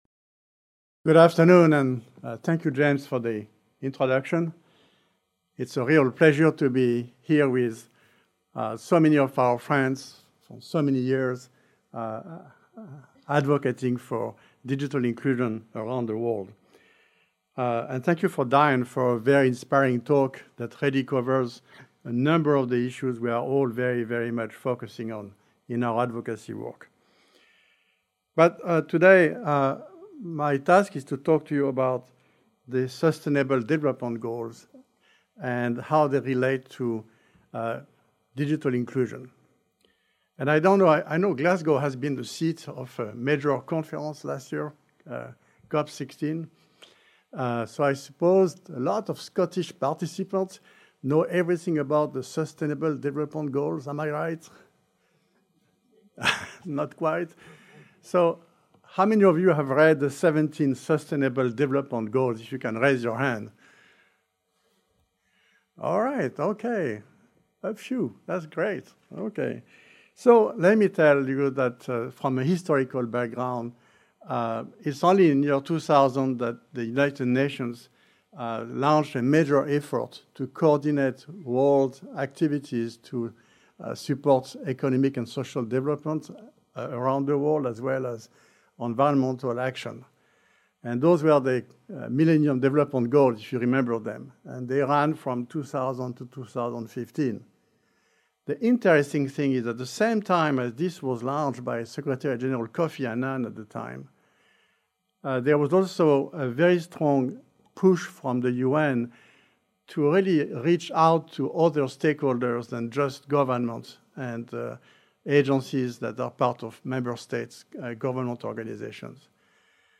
Inclusive Design for Sustainability Conference 2023 - Keynote - The Significance OF SDGs for Digital Inclusion